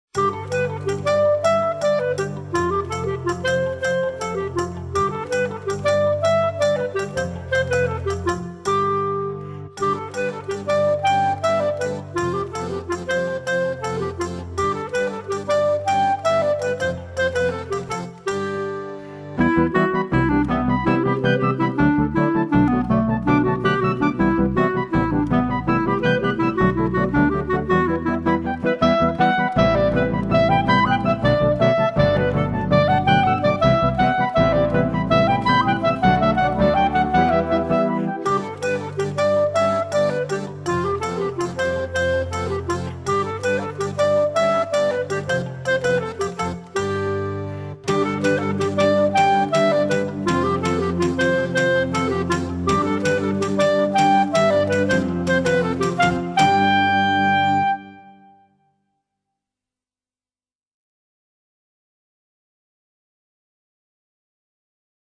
Bb Clarinet + CD (MP3s supplied free on request)